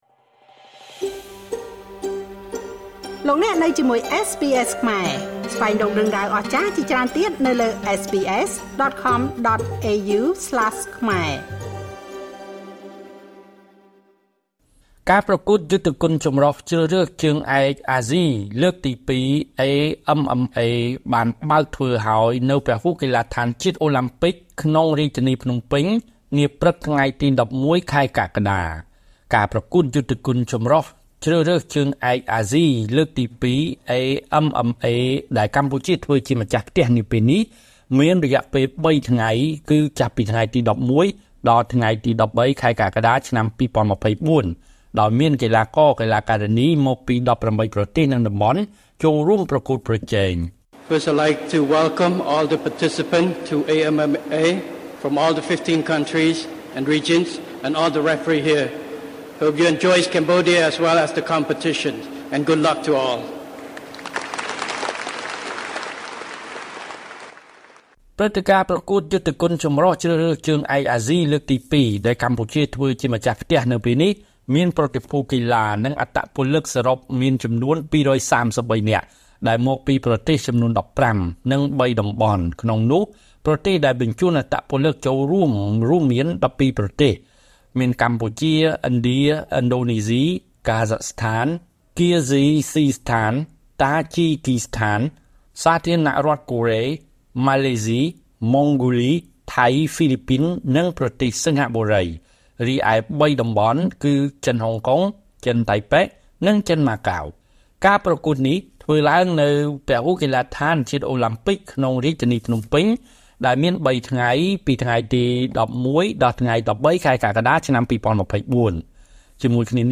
( សំឡេងលោក ហ៊ុន ម៉ាណែត )